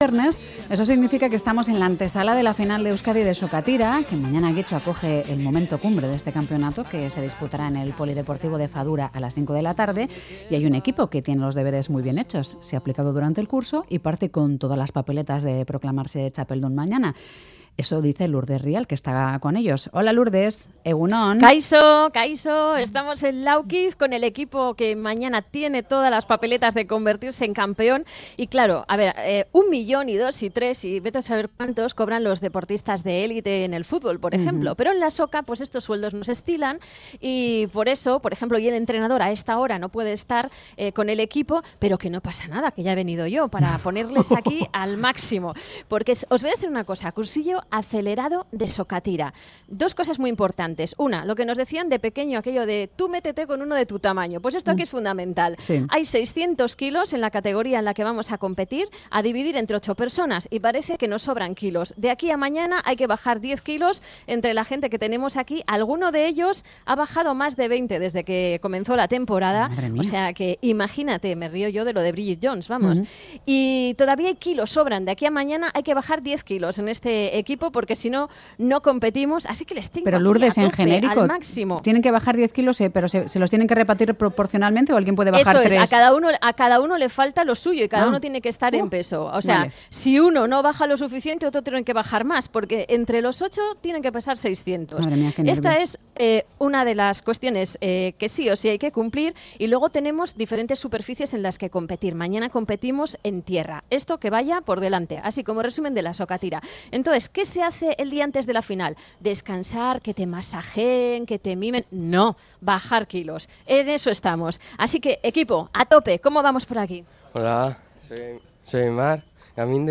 Audio: Mañana se disputa la final de Euskadi de Sokatira en Getxo. 'Boulevard Magazine' se ha desplazado a Laukiz para hablar con algunos miembros del Club Gaztedi.